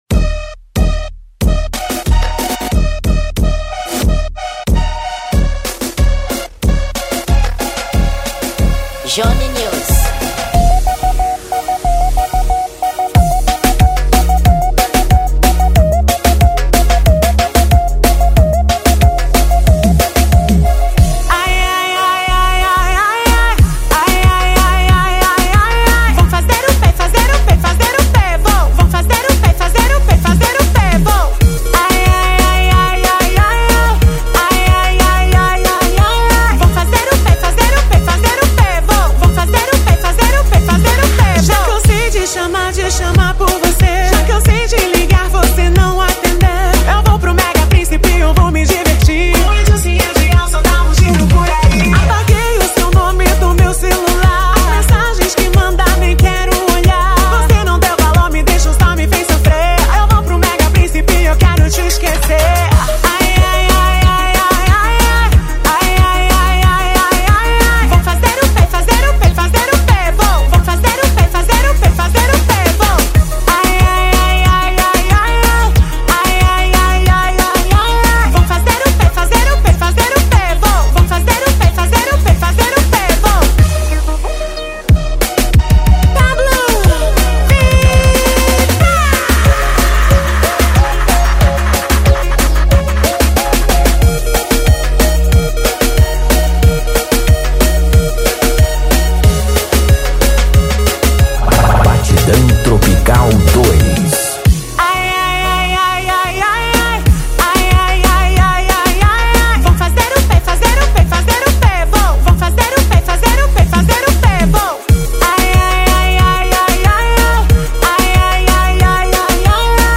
Gênero: Reggaeton